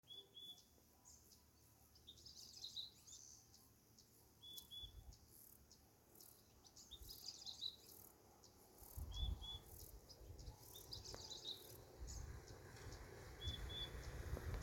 Tangará Común (Euphonia chlorotica)
Nombre en inglés: Purple-throated Euphonia
País: Argentina
Condición: Silvestre
Certeza: Vocalización Grabada